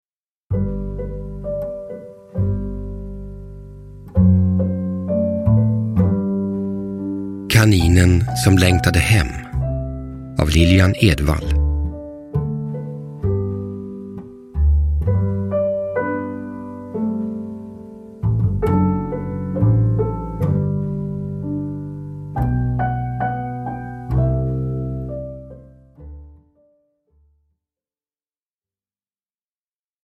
Kaninen som längtade hem – Ljudbok